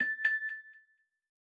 keys-quiet.aifc